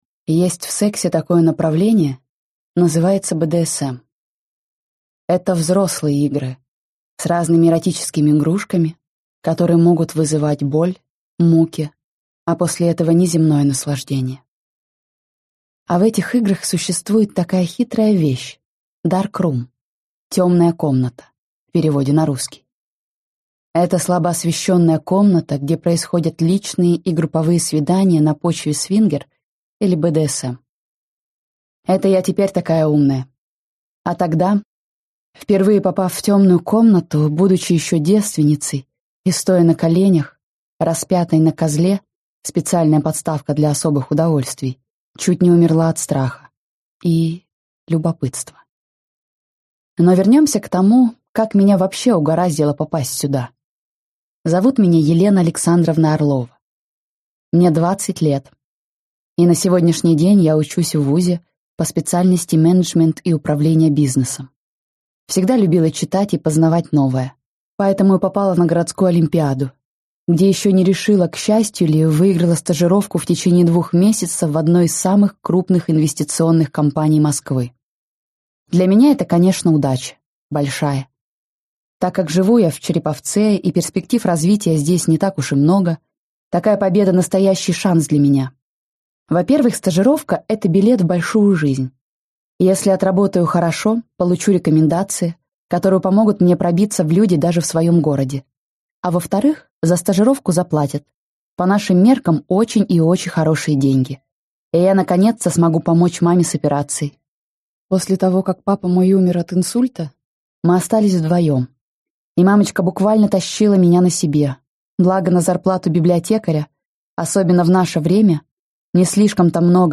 Аудиокнига Темная комната | Библиотека аудиокниг